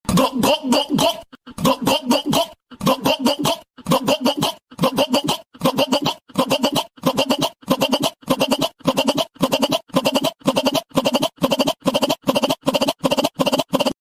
Gogogogogogogo… sound effect
Thể loại: Âm thanh meme Việt Nam
Description: Hiệu ứng âm thanh "Gogogogogogogo..." là một đoạn âm thanh phổ biến trong cộng đồng meme, thường được sử dụng để tăng tính hài hước hoặc nhấn mạnh hành động trong video. Âm thanh này thường được lặp đi lặp lại với tốc độ nhanh, tạo cảm giác hối thúc hoặc gây cười.
gogogogogogogo-sound-effect-www_tiengdong_com.mp3